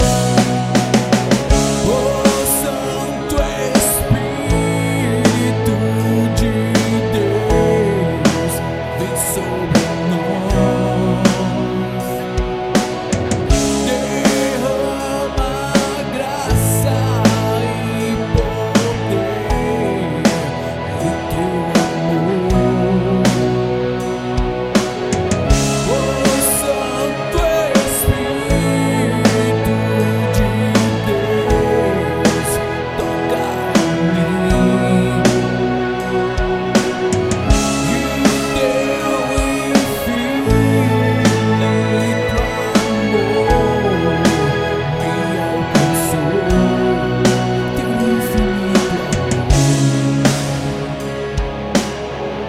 pRIMEIRA prévia DO HINO
este foi o primeiro teste que fiz no fim do ano passado.
01-Infinito-amor-de-Deus-Refrao-Orcherstra.2-banda-MIDI.mp3